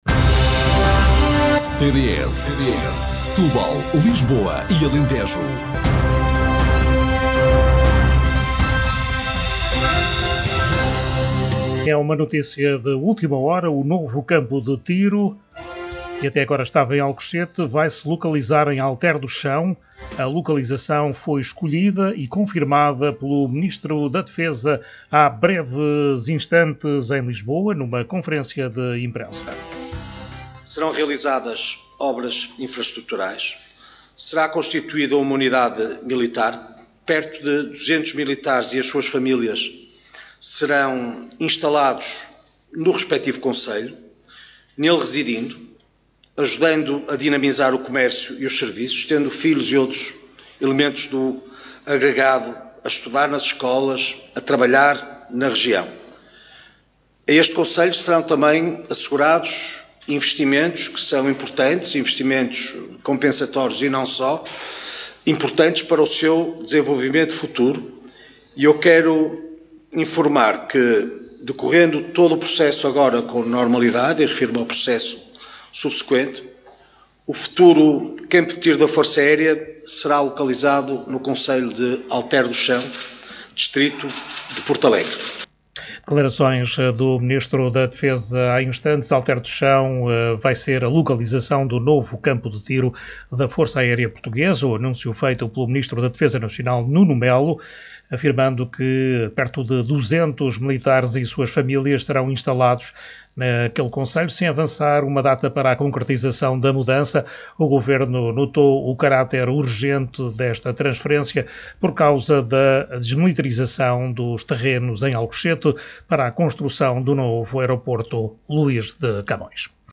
Oiça as declarações de Nuno Melo já transmitidas na emissão rádio da TDS.